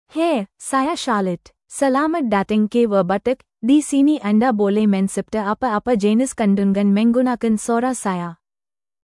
CharlotteFemale Malayalam AI voice
Charlotte is a female AI voice for Malayalam (India).
Voice sample
Listen to Charlotte's female Malayalam voice.
Charlotte delivers clear pronunciation with authentic India Malayalam intonation, making your content sound professionally produced.